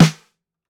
DB SWUNG_SNARE.wav